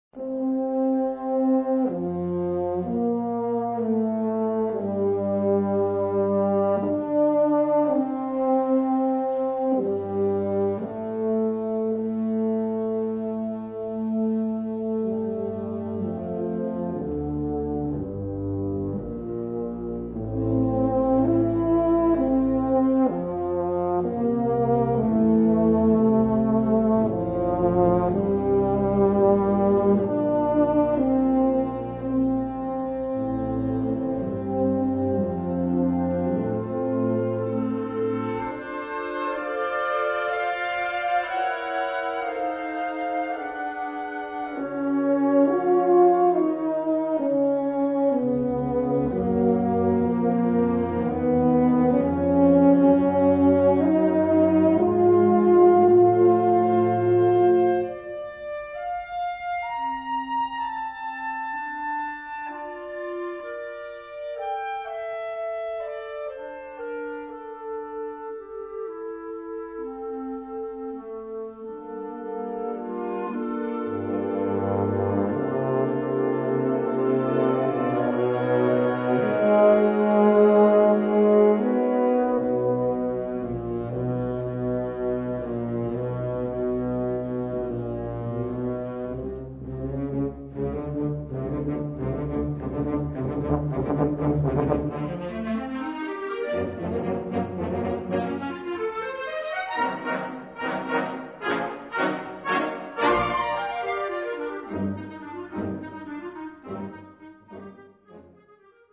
Catégorie Harmonie/Fanfare/Brass-band
Sous-catégorie Musique à vent contemporaine (1945-présent)
Instrumentation Ha (orchestre d'harmonie); CB (Concertband)